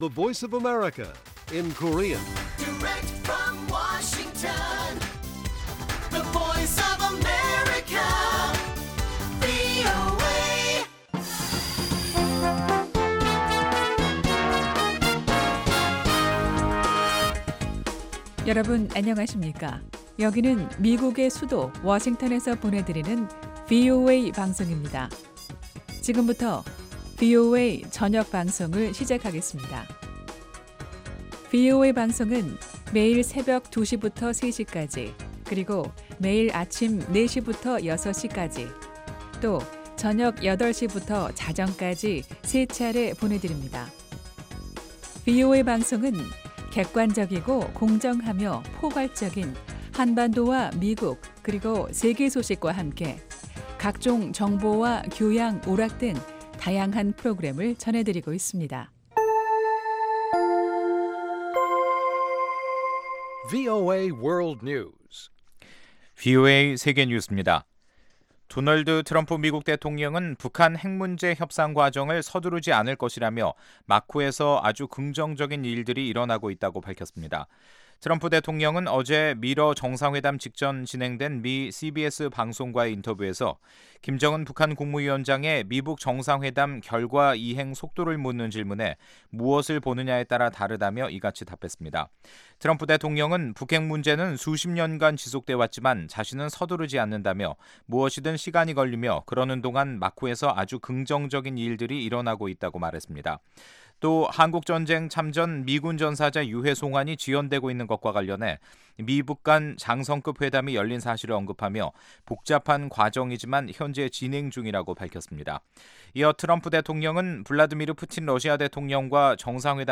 VOA 한국어 간판 뉴스 프로그램 '뉴스 투데이', 2018년 7월 17일 1부 방송입니다. 트럼프 미국 대통령은 블라디미르 푸틴 러시아 대통령도 북한 핵 문제 해결을 간절히 원한다는 것을 확신한다고 밝혔습니다. 미국의 한반도 전문가들은 미-북 간 유해 송환 합의가 신뢰 구축에 도움이 될 것으로 내다봤습니다.